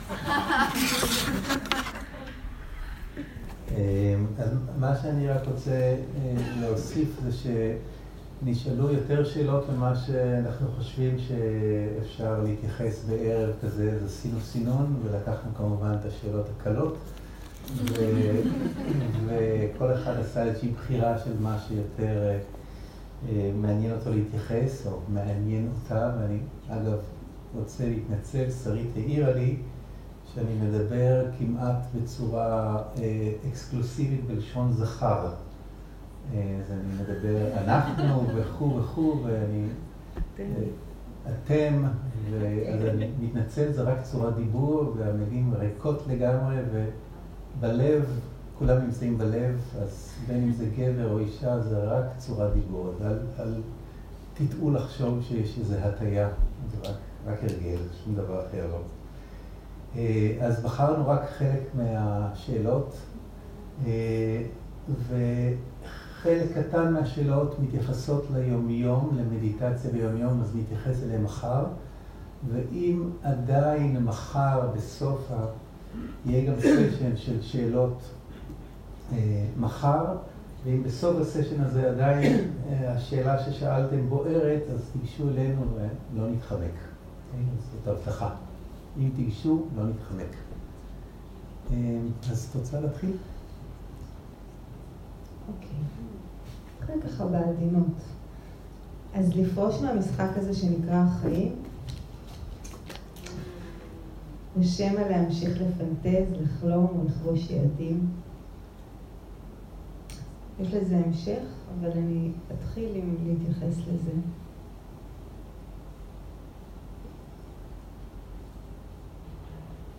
סוג ההקלטה: שאלות ותשובות
איכות ההקלטה: איכות גבוהה